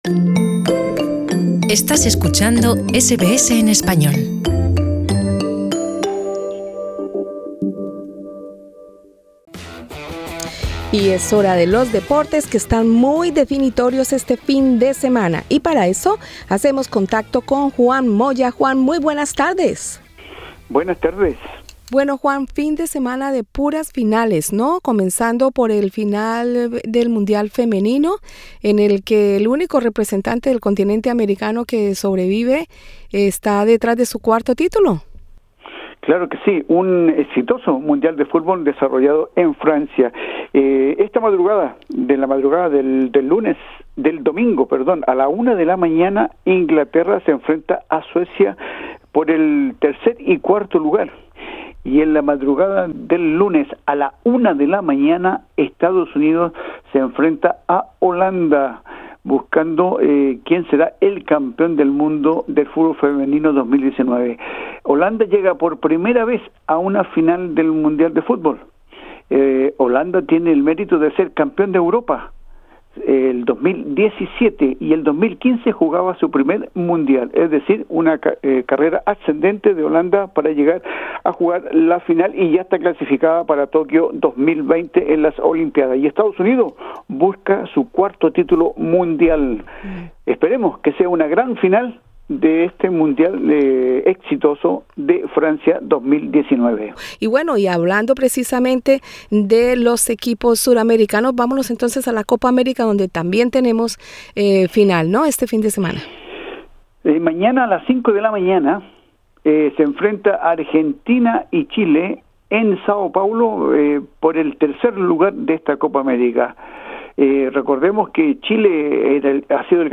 resumen deportivo